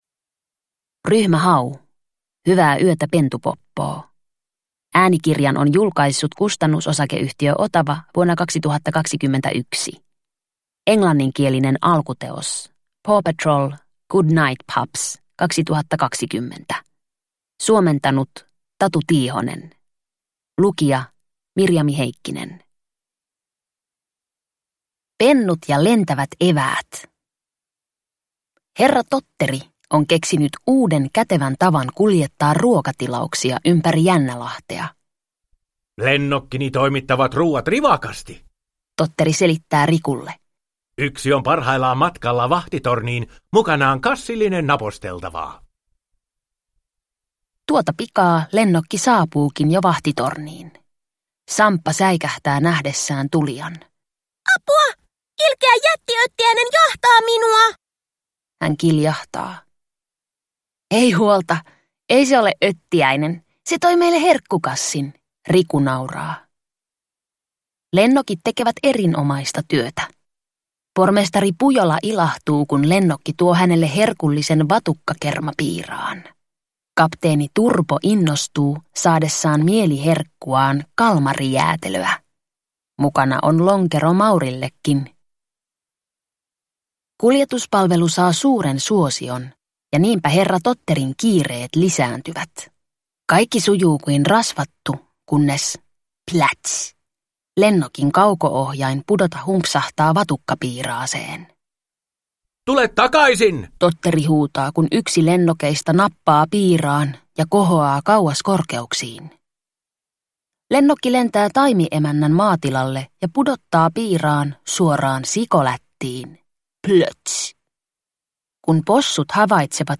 Ryhmä Hau - Hyvää yötä, pentupoppoo – Ljudbok – Laddas ner